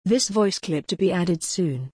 Sings: Mezzo Soprano G3 to E5